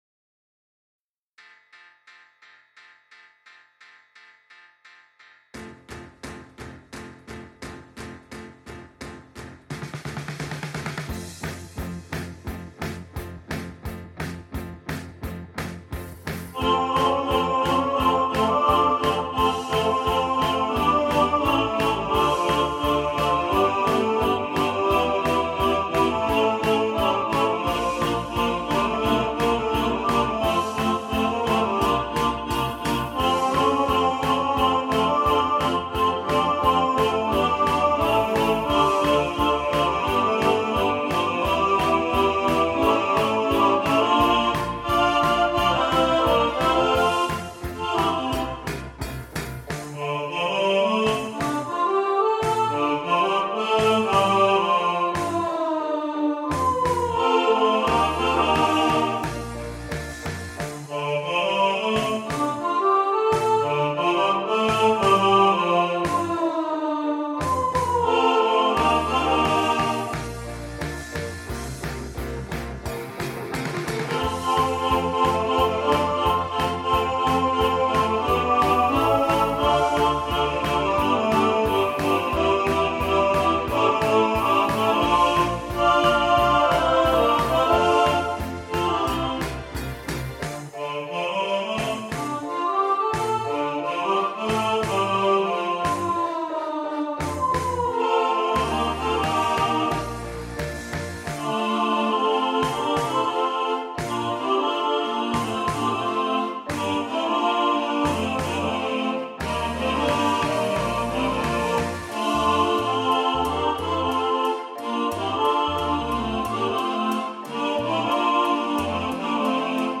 Mr Blue Sky – All Voices | Ipswich Hospital Community Choir